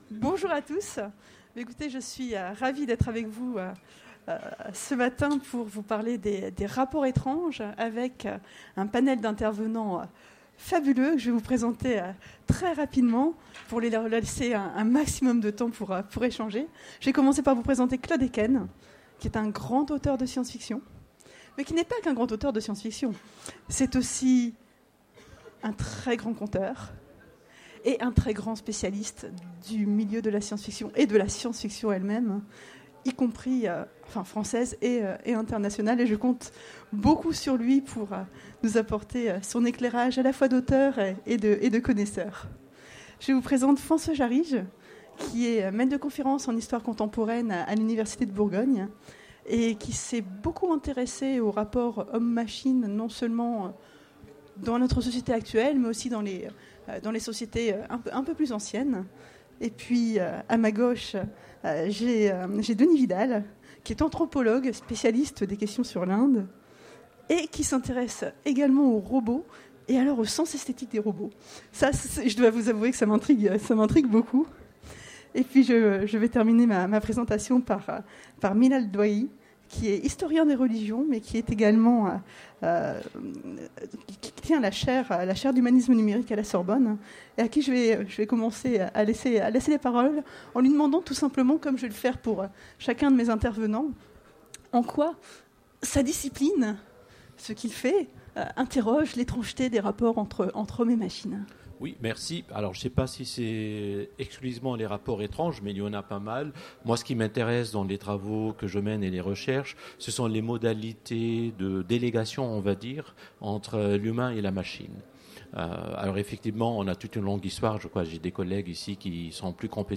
Utopiales 2016 : Conférence Des rapports étranges